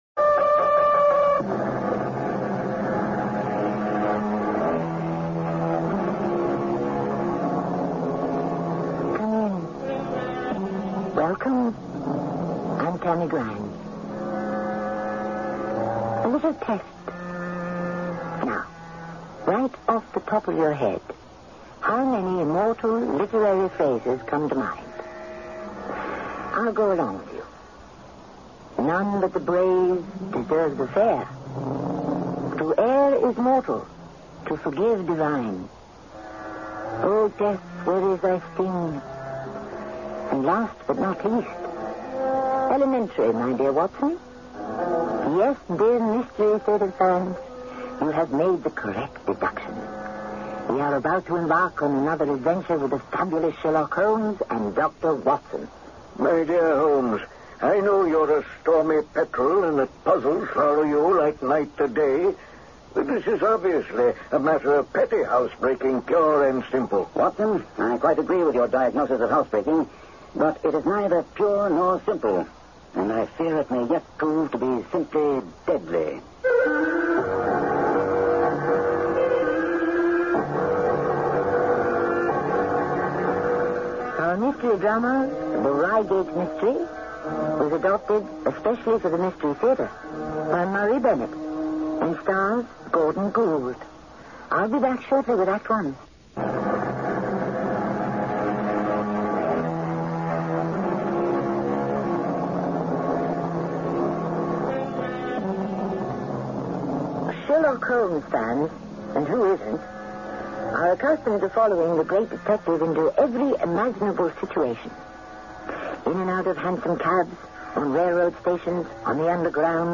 Radio Show Drama with Sherlock Holmes - The Reigate Mystery 1982